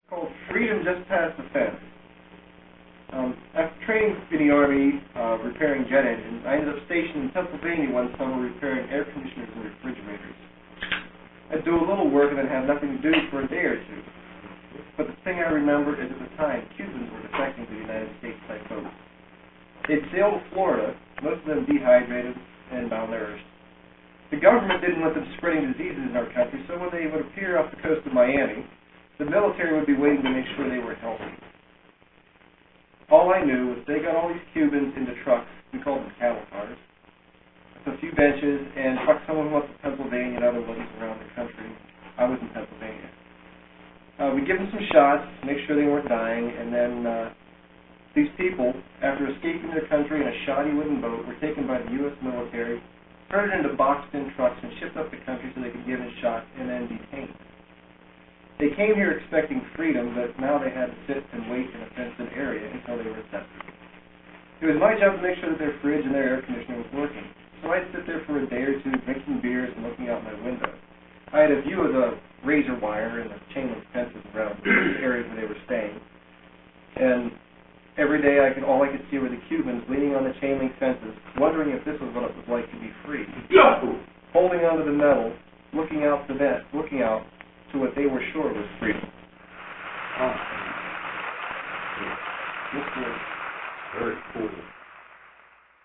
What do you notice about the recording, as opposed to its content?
These live recording were made 3/13/02 in Hendersonville, outside of Nashville.